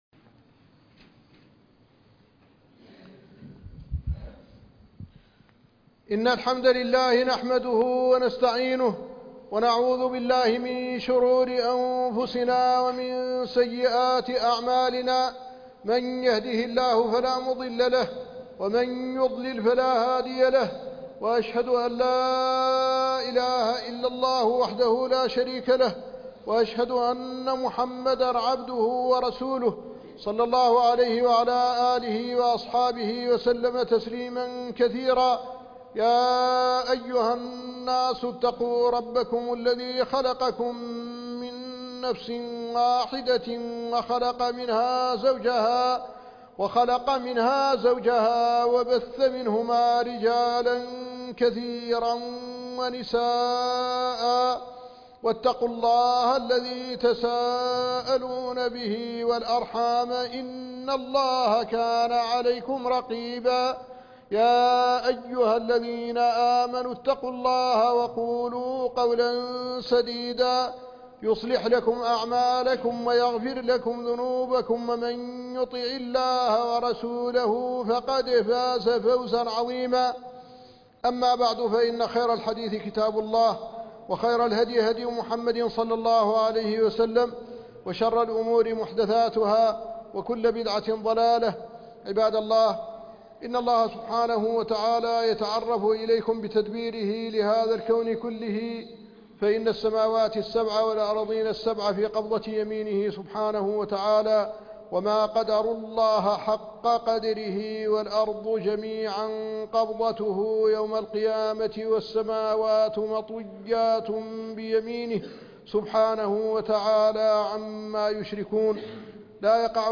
مسؤولية المسلمين تجاه غزة - خطبة الجمعة